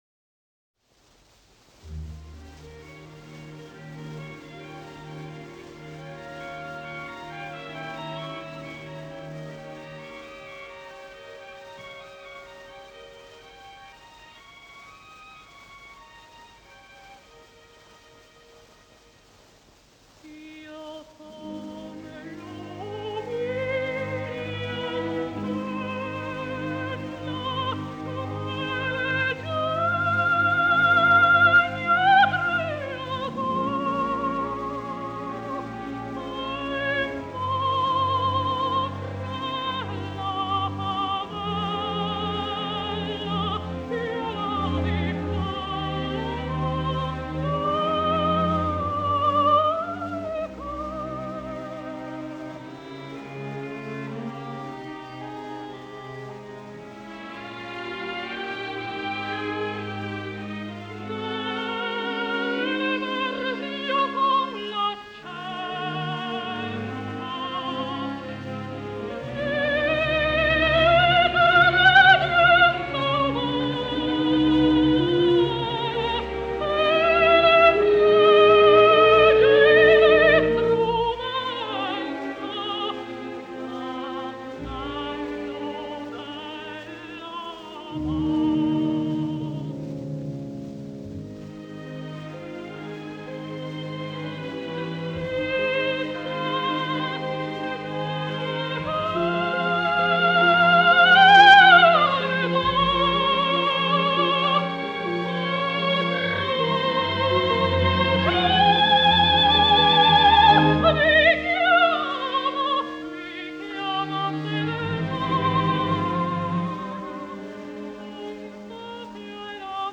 Предлагаемая ария